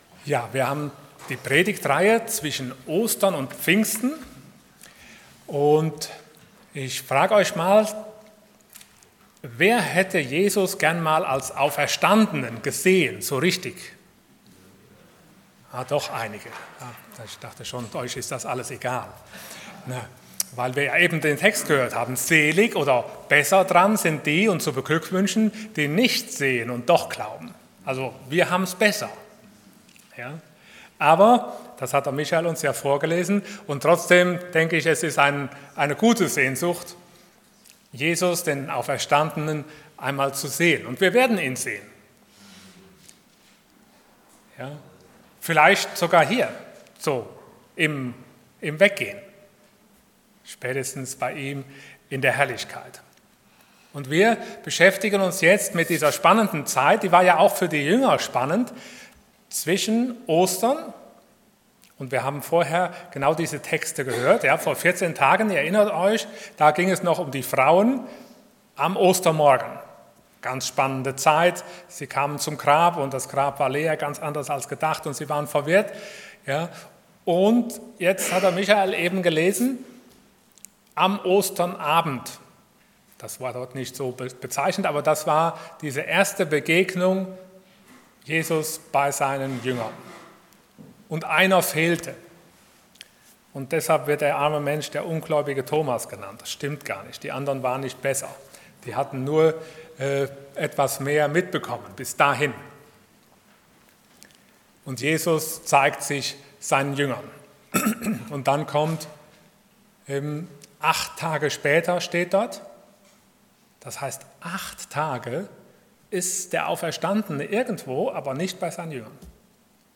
Passage: John 21:1-14 Dienstart: Sonntag Morgen